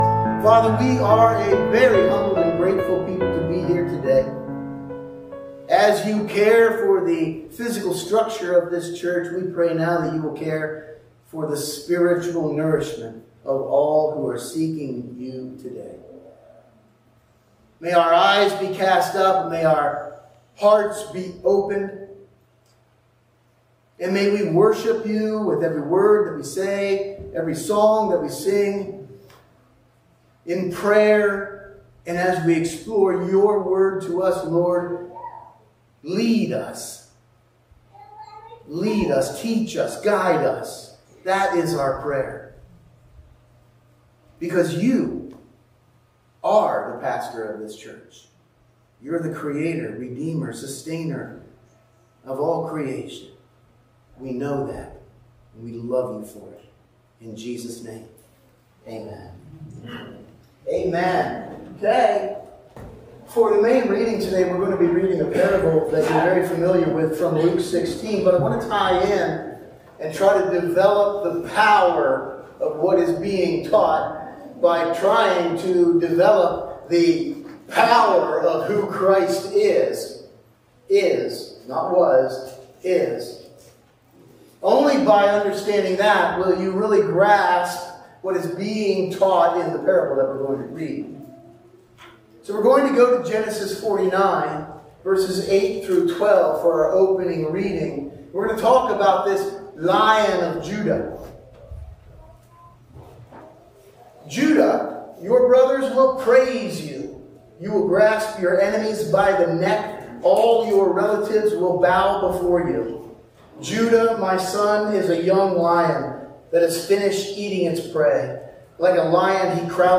Sunday Morning Service – March 23, 2025